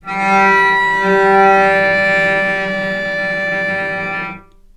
healing-soundscapes/Sound Banks/HSS_OP_Pack/Strings/cello/sul-ponticello/vc_sp-G3-mf.AIF at 01ef1558cb71fd5ac0c09b723e26d76a8e1b755c
vc_sp-G3-mf.AIF